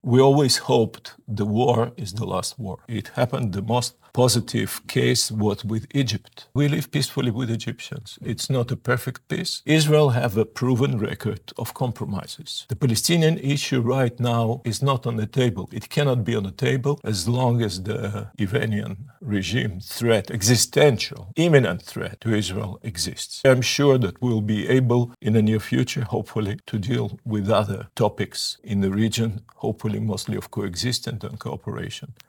ZAGREB - Dok se cijeli svijet pita koliko će trajati rat na Bliskom istoku i kakve će globalne posljedice ostaviti, odgovore na ta pitanja potražili smo u Intervjuu tjedna Media servisa od izraelskog veleposlanika u Zagrebu Garyja Korena.